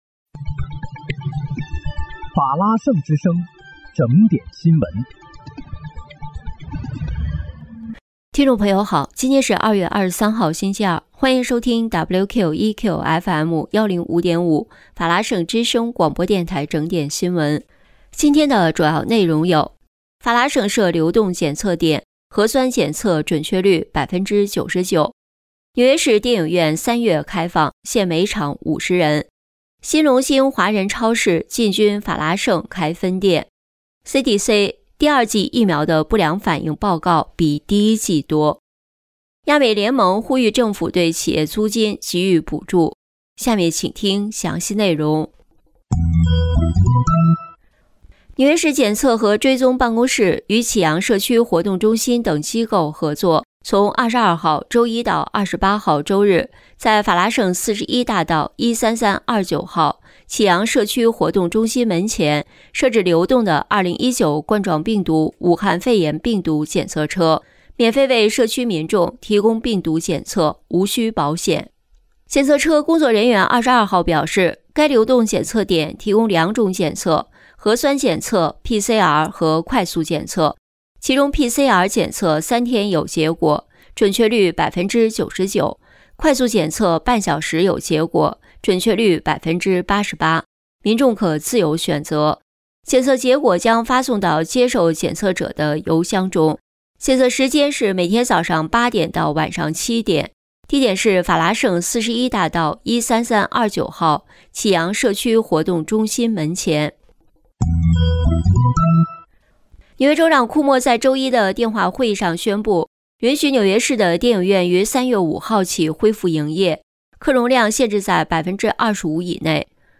2月23日（星期二）纽约整点新闻